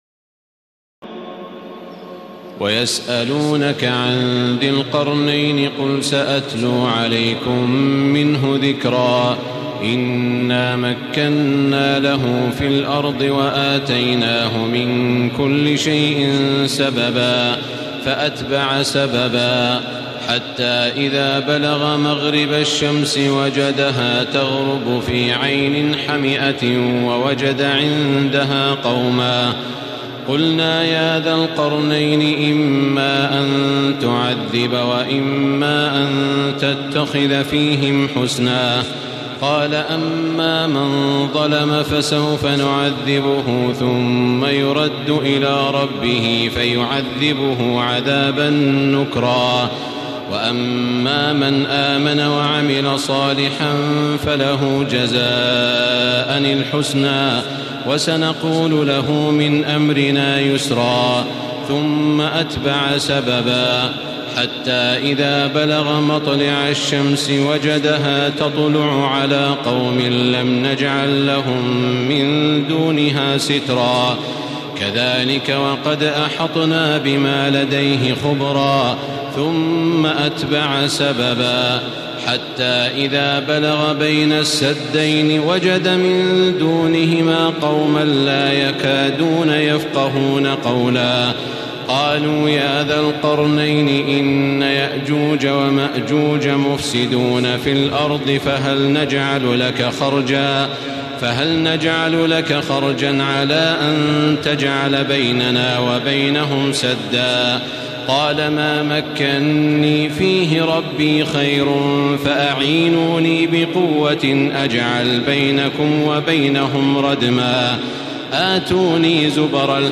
تراويح الليلة السادسة عشر رمضان 1435هـ من سورتي الكهف (83-110) و مريم كاملة Taraweeh 16 st night Ramadan 1435H from Surah Al-Kahf and Maryam > تراويح الحرم المكي عام 1435 🕋 > التراويح - تلاوات الحرمين